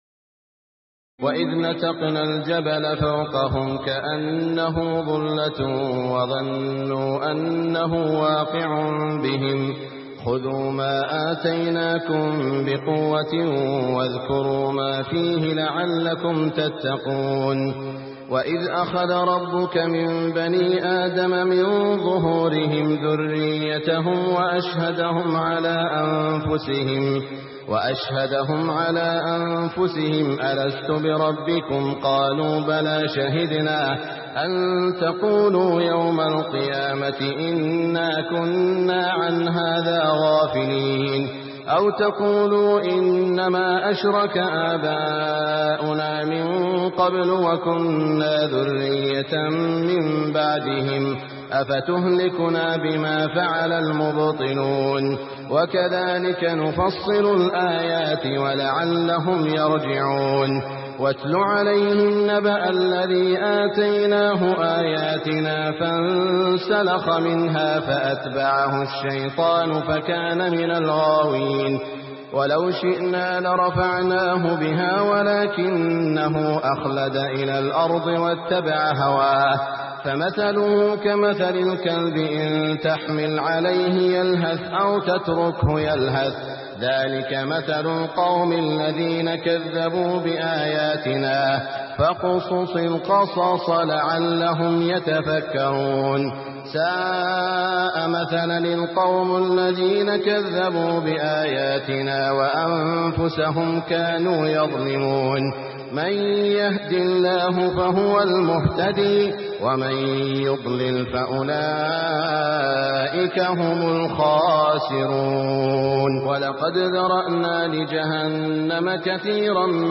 تراويح الليلة الثامنة رمضان 1423هـ من سورتي الأعراف (171-206) والأنفال (1-40) Taraweeh 8 st night Ramadan 1423H from Surah Al-A’raf and Al-Anfal > تراويح الحرم المكي عام 1423 🕋 > التراويح - تلاوات الحرمين